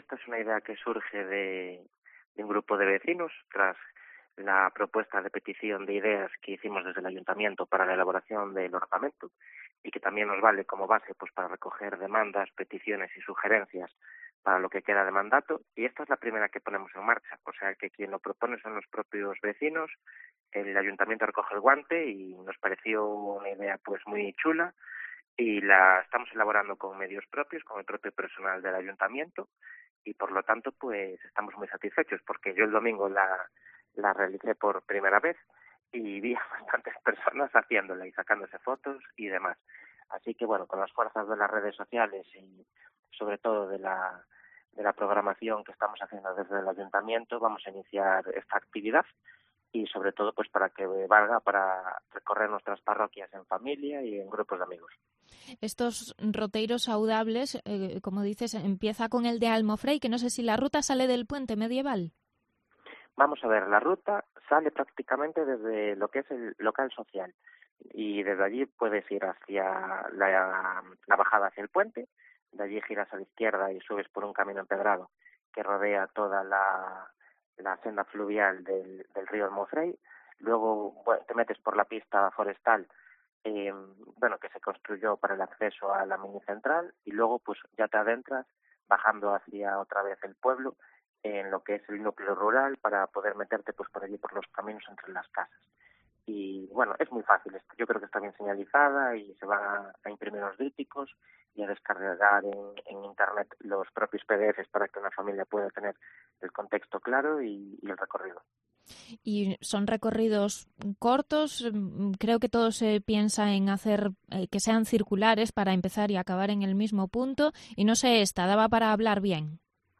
Entrevista al alcalde de Cerdedo-Cotobade, Jorge Cubela, sobre los 'Roteiros Saudables' que se van a señalizar y que responden a una propuesta vecinal. Partió de los propios vecinos indicar la dificultad de los recorridos según si permiten caminar e ir hablando con comodidad.